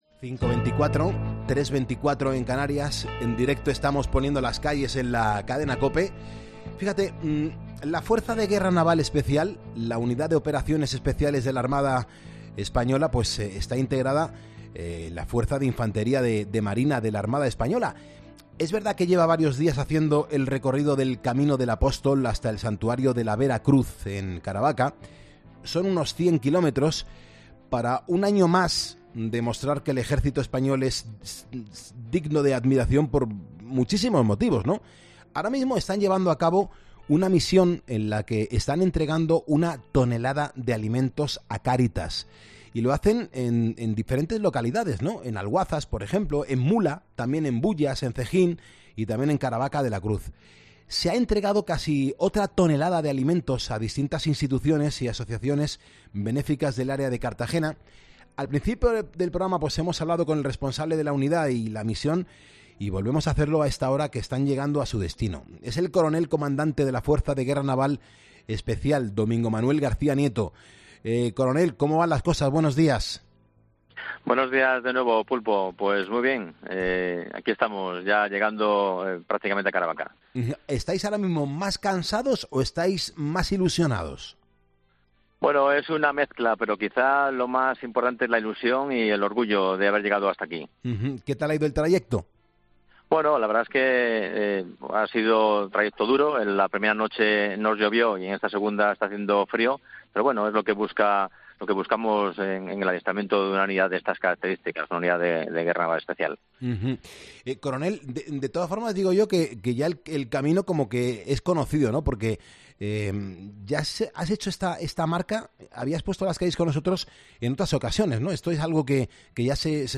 La Fuerza de Guerra Naval Especial en directo en 'Poniendo las calles' con su misión más solidaria